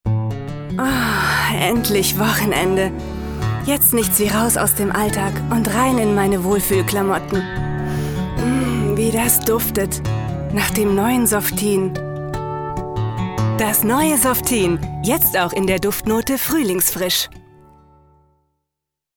Sprecherin aus Berlin mit vielseitig einsetzbarer Stimme - frisch/jugendlich - sachlich/seriös - sanft & warm.
Sprechprobe: Werbung (Muttersprache):